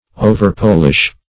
overpolish - definition of overpolish - synonyms, pronunciation, spelling from Free Dictionary
Overpolish \O`ver*pol"ish\, v. t. To polish too much.